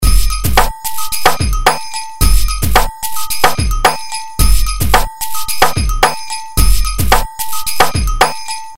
描述：包含鼓、小鼓、风铃和方铃的桑巴舞循环。
Tag: 110 bpm Samba Loops Drum Loops 1.47 MB wav Key : Unknown